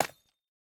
Minecraft Version Minecraft Version 21w07a Latest Release | Latest Snapshot 21w07a / assets / minecraft / sounds / block / calcite / break4.ogg Compare With Compare With Latest Release | Latest Snapshot